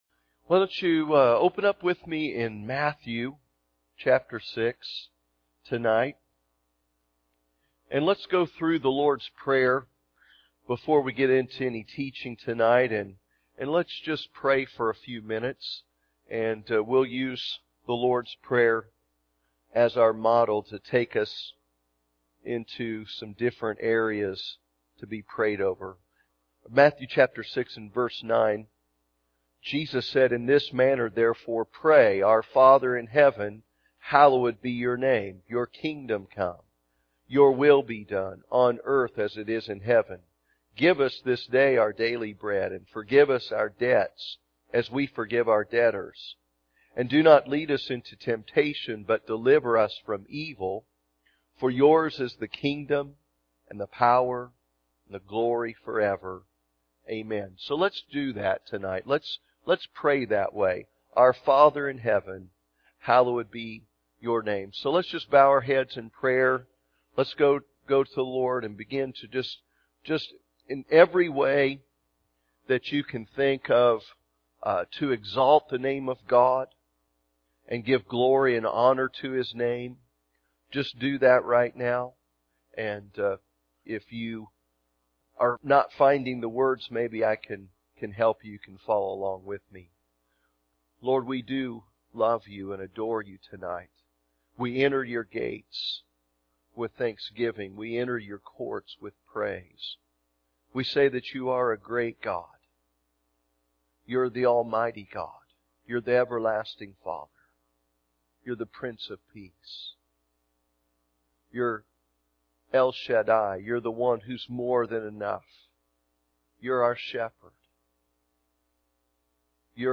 Wednesday Evening Service